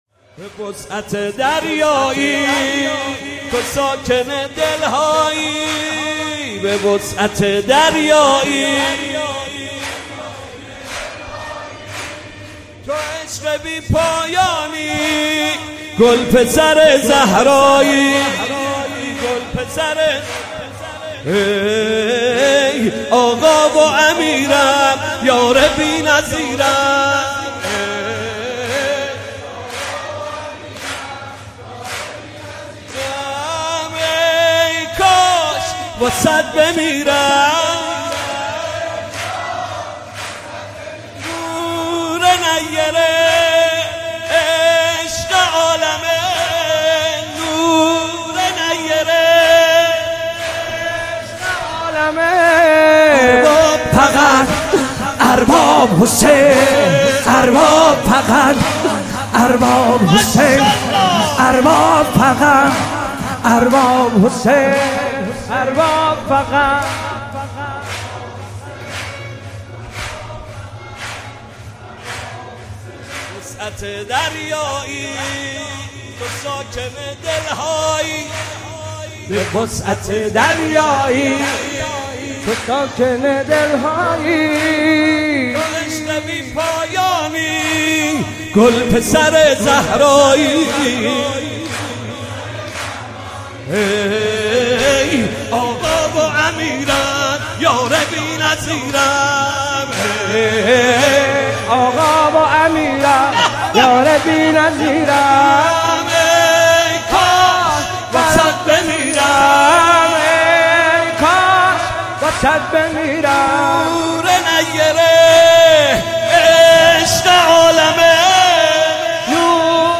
مولودی جدید
دوشنبه ۱۹ فروردین ۱۳۹۸ هیات مکتب الزهرا (س) تهران